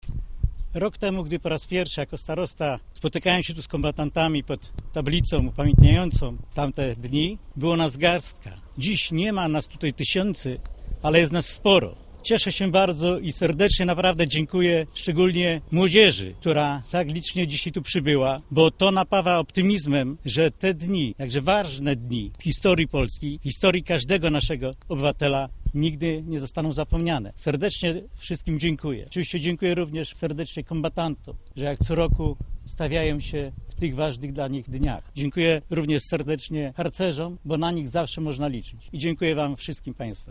Adam Myrda, starosta powiatu lubińskiego.